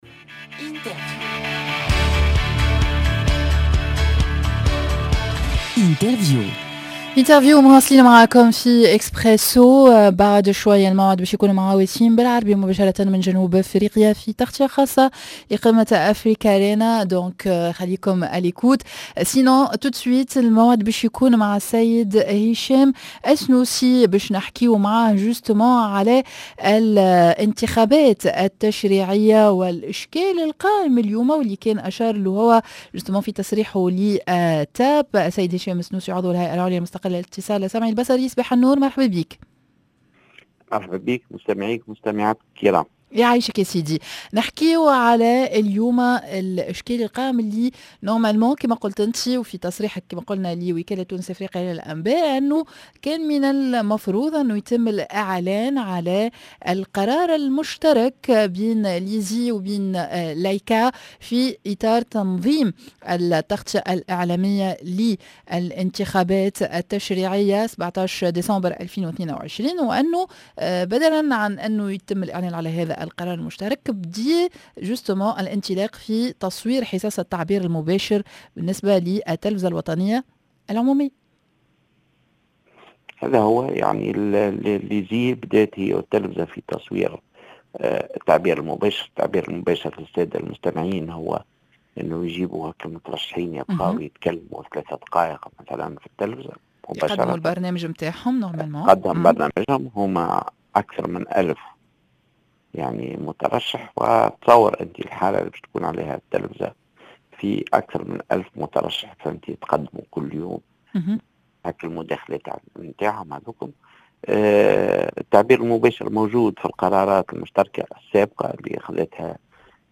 L'interview: هشام السنوسي عضو الهيئة العليا المستقلة للإتصال السمعي البصري